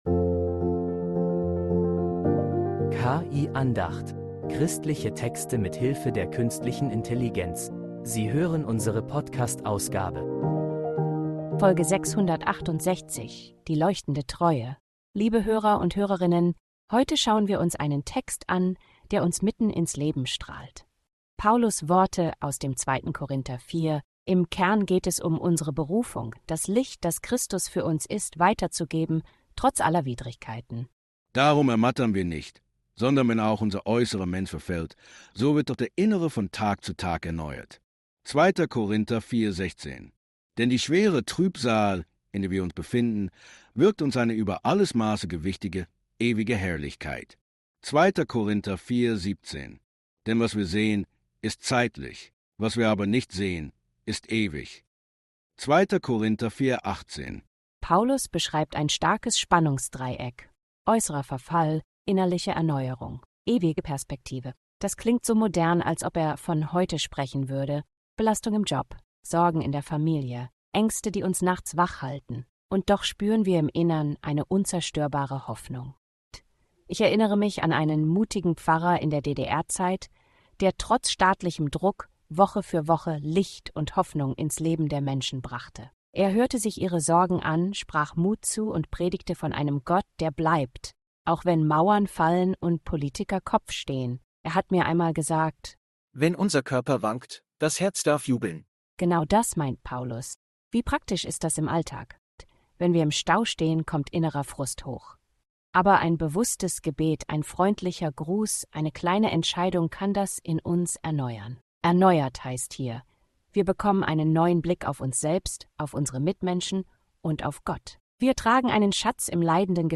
Eine Andacht über Paulus’